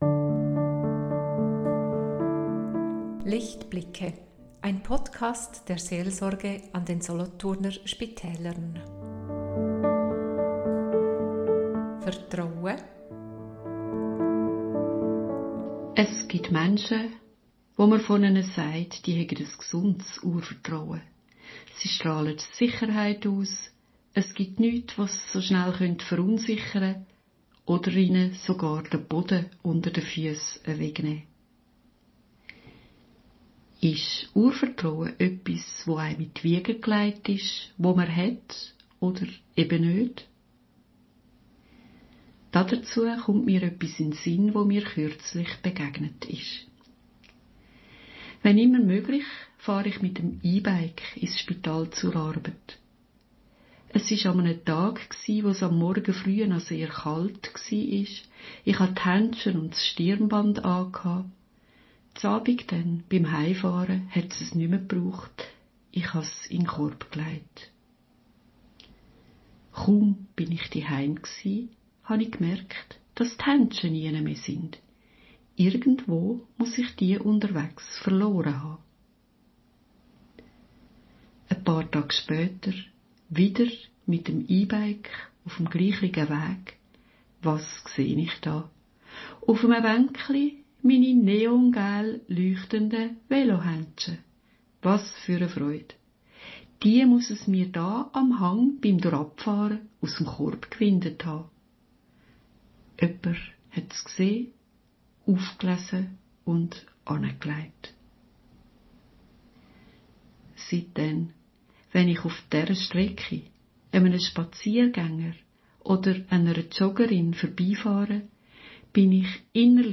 In kurzen, einfühlsamen Monologen teilen unsere Seelsorgerinnen und Seelsorger Gedanken, die ermutigen, trösten und neue Zuversicht schenken sollen.